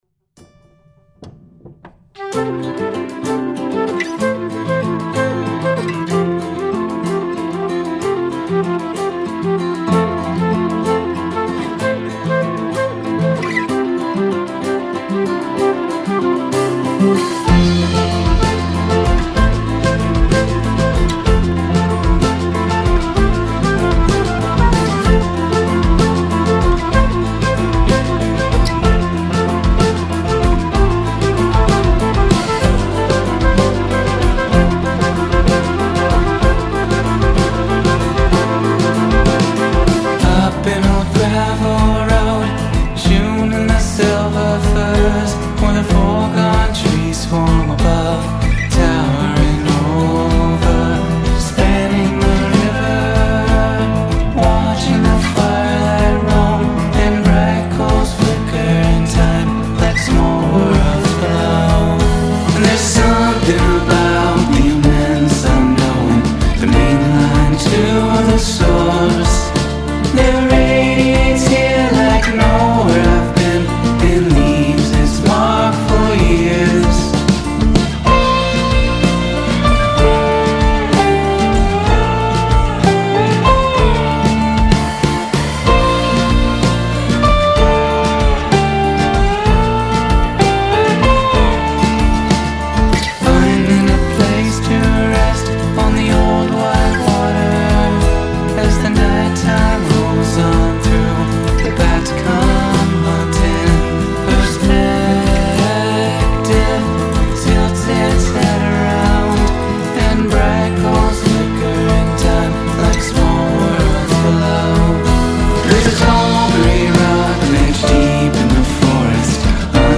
the orchestral and melodic sensibilities
the flute as the main attraction.